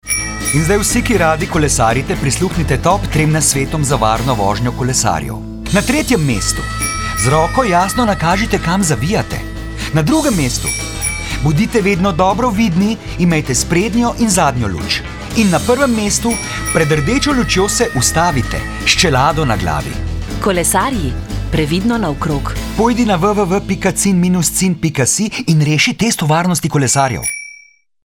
Radijski oglas s tremi nasveti za kolesarje  (mp3)